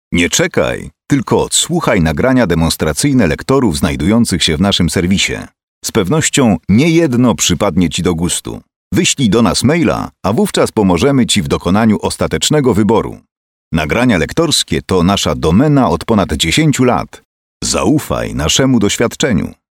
Celebrity Male 30-50 lat
His voice is perceived as prestigious and well-received by a wide audience.
Lektor
Nagranie demonstracyjne